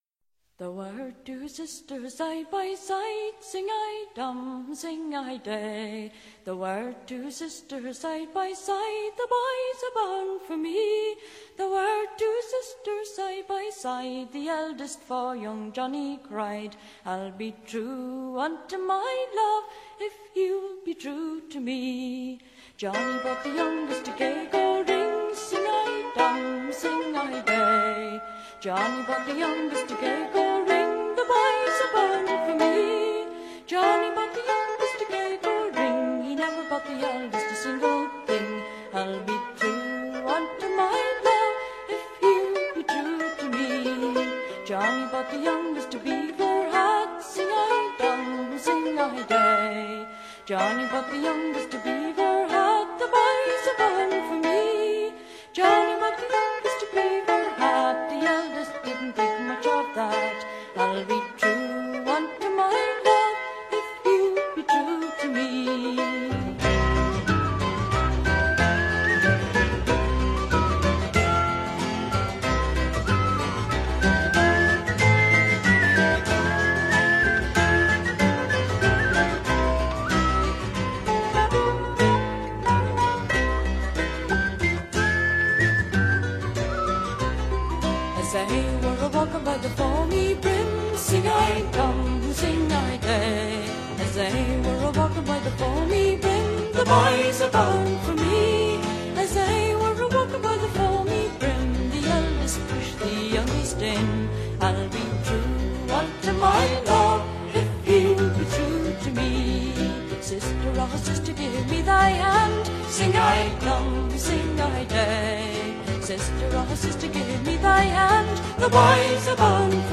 Intervista a Moya Brennan (Clannad) | Rocktrotter 16-5-22